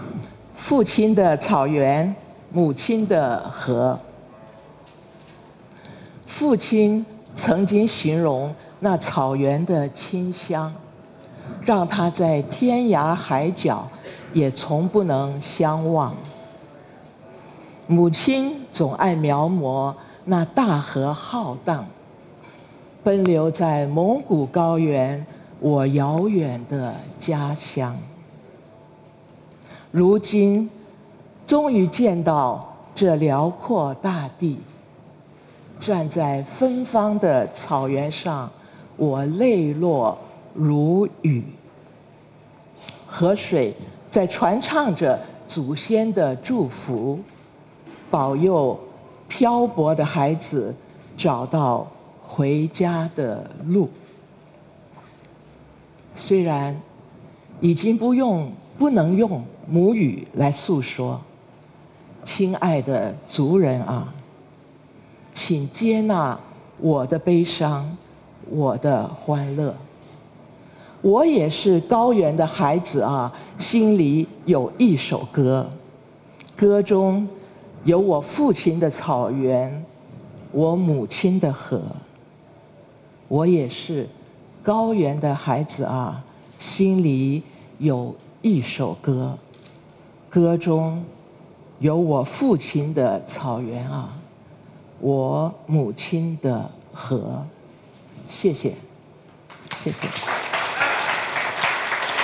席慕容诗歌朗诵《父亲的草原母亲的河》